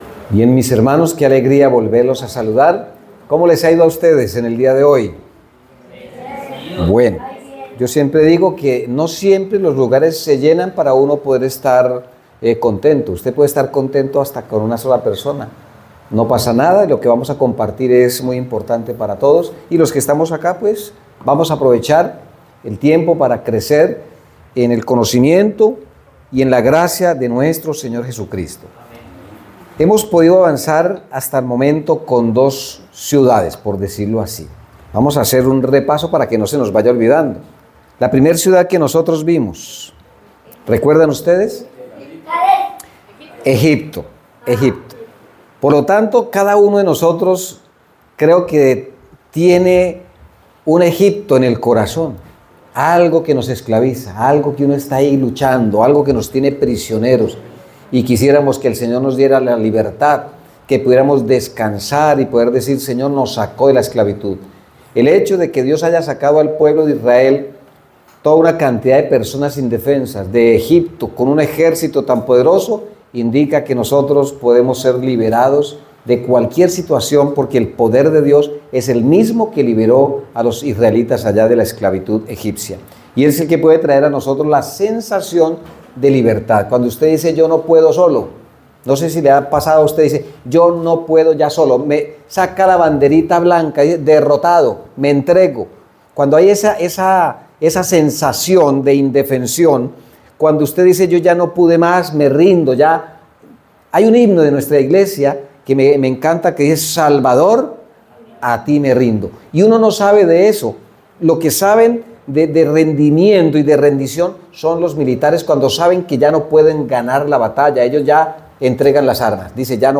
Te sientes cansado, fatigado y no encuentras una solución a ese problema, aprende por medio de este mensaje espiritual que hacer ante estas circunstancias de la vida.